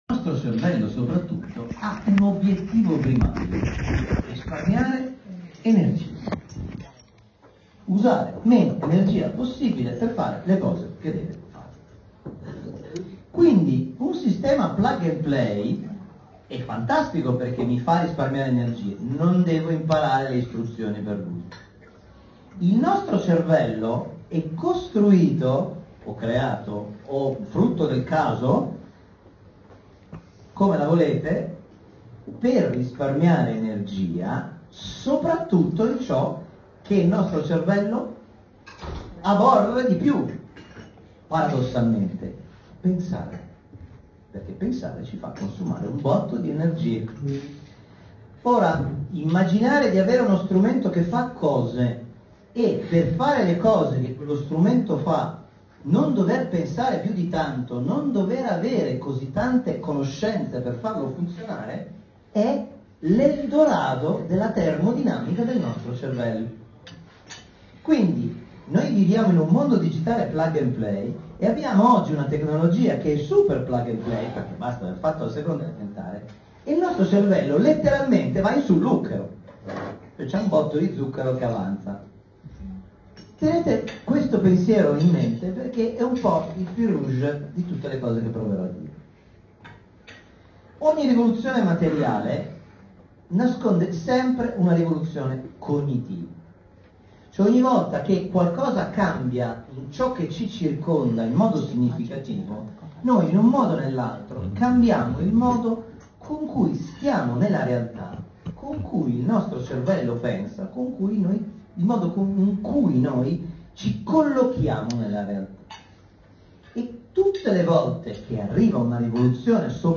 Il suo intervento ha guidato i soci in una riflessione sull’etica dell’intelligenza artificiale, proponendo uno sguardo capace di coniugare innovazione, responsabilità e centralità della persona.